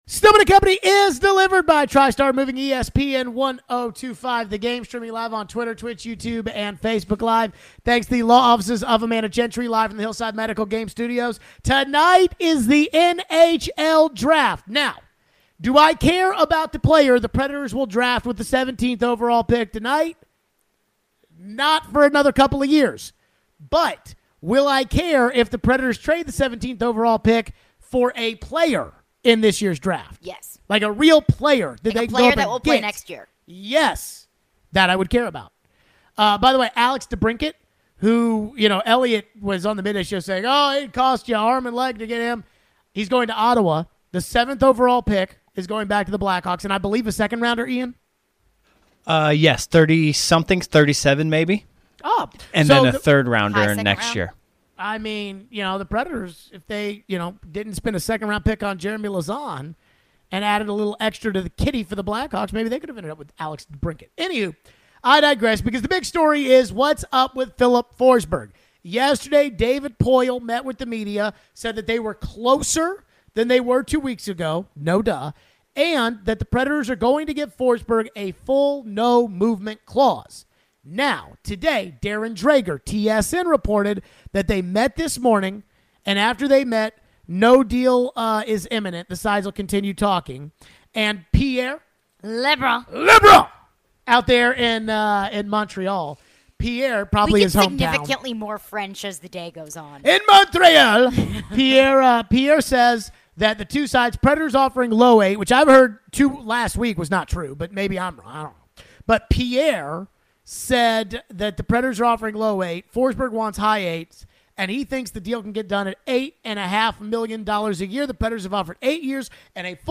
Do we think the Preds should play Ryan McDonagh on the top pair with Roman Josi? We take your phones.